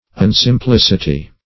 Search Result for " unsimplicity" : The Collaborative International Dictionary of English v.0.48: Unsimplicity \Un`sim*plic"i*ty\, n. Absence of simplicity; artfulness.
unsimplicity.mp3